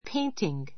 painting A1 péintiŋ ペ インティン ぐ 名詞 （絵の具で） 絵を描 か くこと ; （絵の具で描いた） 絵 , 油絵, 水彩 すいさい 画 drawing 名 an oil painting by Picasso an oil painting by Picasso ピカソによる油絵 He went to Paris to study painting.